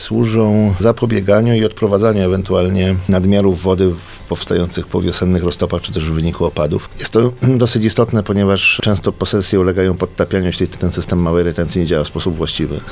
Dlaczego ta ostatnia inwestycja związana z tymi akwenami jest szczególnie ważna, mówi wójt Krzysztof Sobczak: